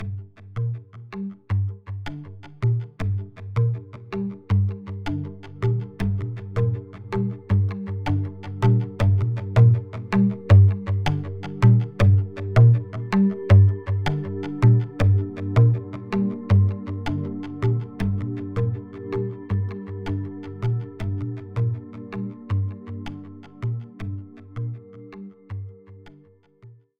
accentuated background calm cinematic delay drum drumming drums sound effect free sound royalty free Movies & TV